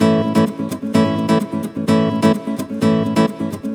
VEH2 Nylon Guitar Kit 128BPM
VEH2 Nylon Guitar Kit - 1 A maj.wav